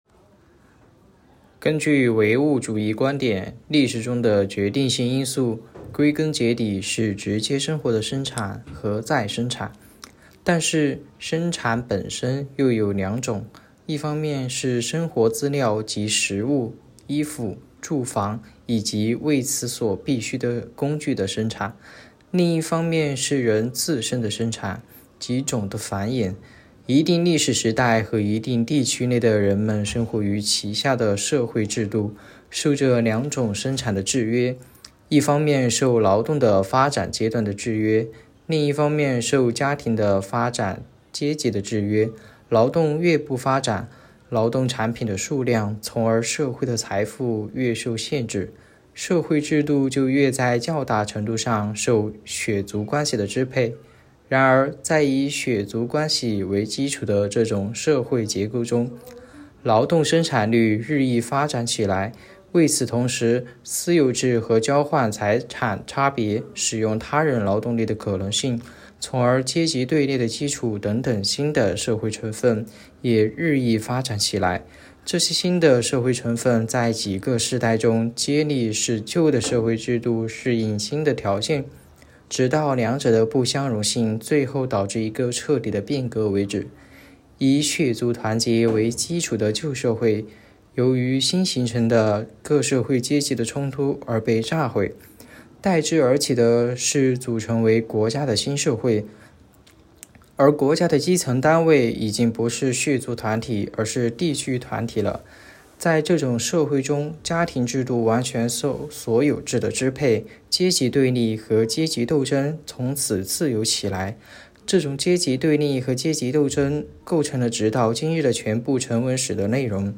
“读经典、悟原理”——2025年西华大学马克思主义经典著作研读会接力诵读（05期）